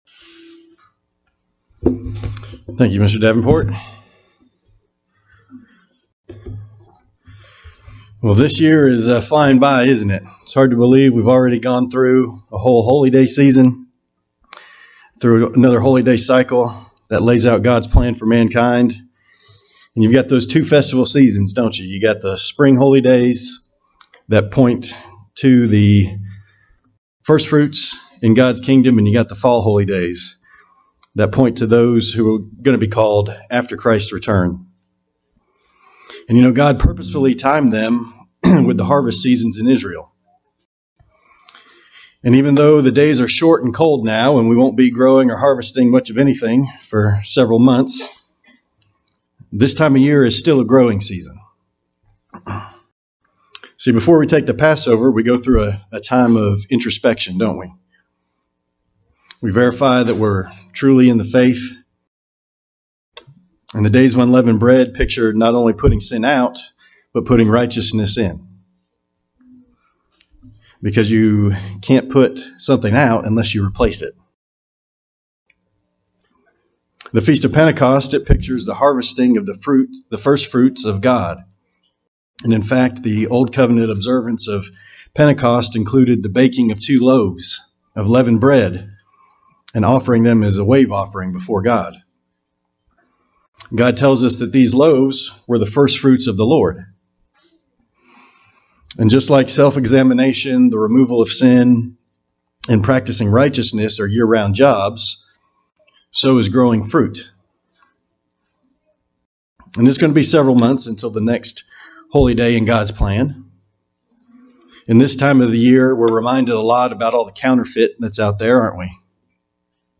Sermons
Given in Huntsville, AL